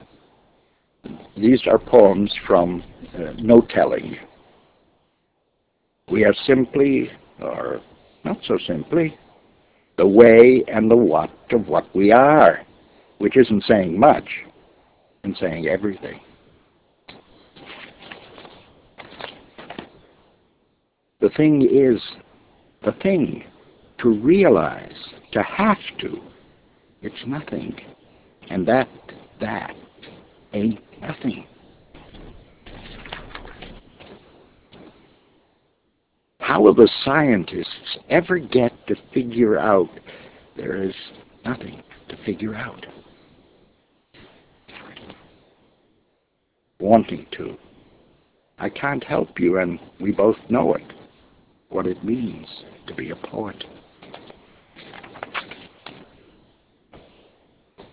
Reading from No Telling